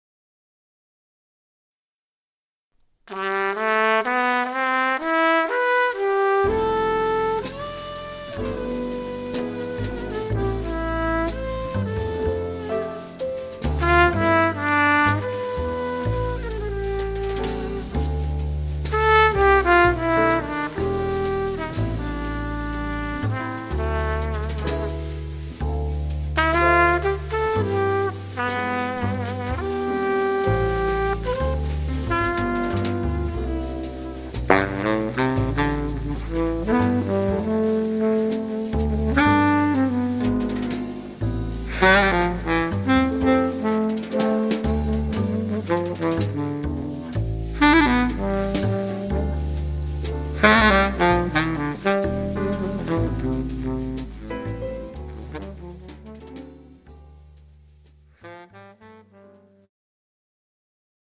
The Best In British Jazz
Recorded at Red Gables Studio, London 2010